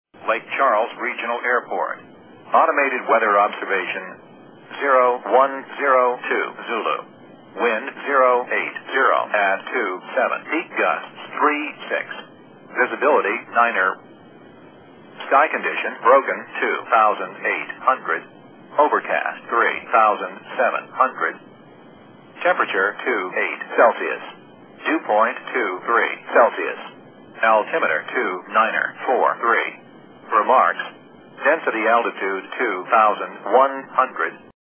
ハリケーン・アイクの情報と言うかATISやASOS,AWOSの情報を集めようと、国際電話でまた挑戦しました。
ASOS 0102Z ハリケーンの右側にある空港で唯一ASOSを聞く事ができました。まだ風は40以下ですが、後には聞えなくなりました、ハリケーンや台風は右側が強くなるので、何か変わった物が録音できないかと試してみました。
LakeCharles_LCH_ASOS.mp3